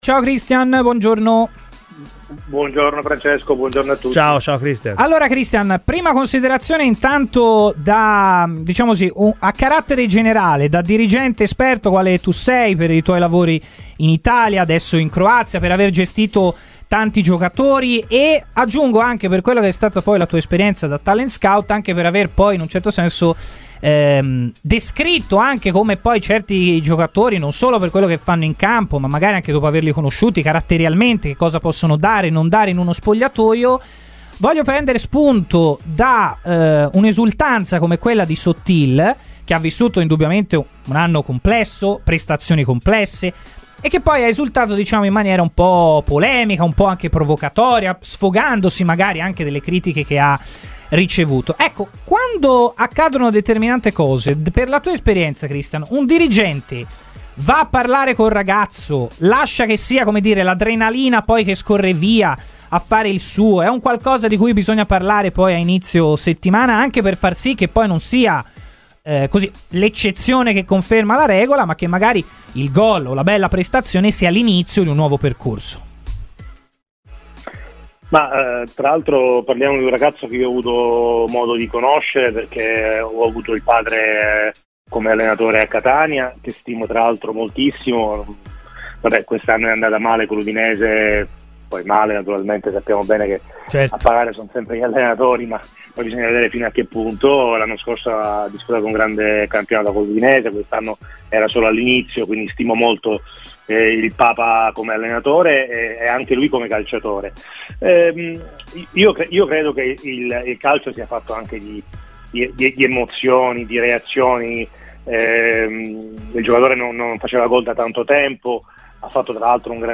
ha parlato a Radio FirenzeViola , durante la trasmissione Chi si compra .